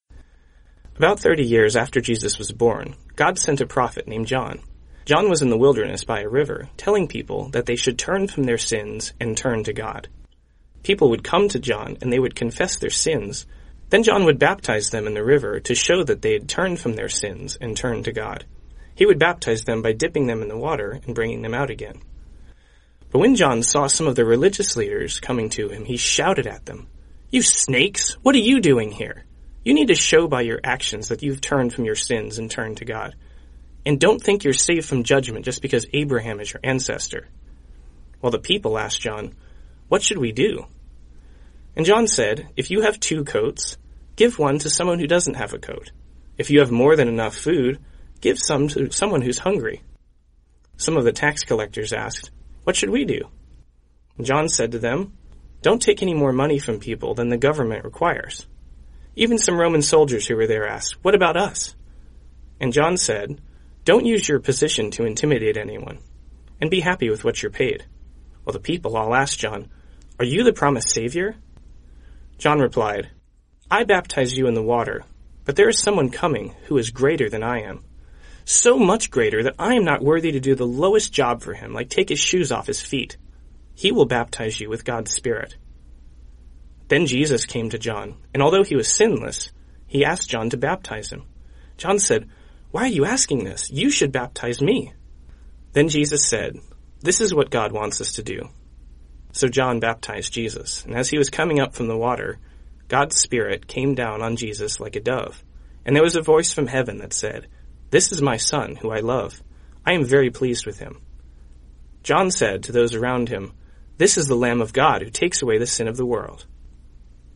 Deepen your intimacy with God by listening to an oral Bible story each day.